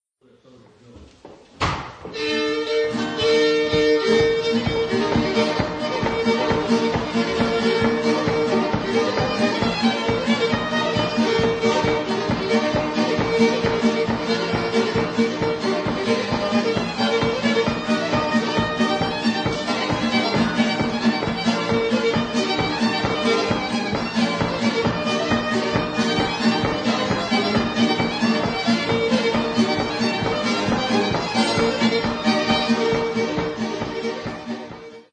fiddle
guitar
banjo
Recorded at the Lily Dale schoolhouse December 1, 1984.